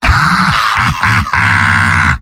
Giant Robot lines from MvM. This is an audio clip from the game Team Fortress 2 .
Demoman_mvm_m_laughevil03.mp3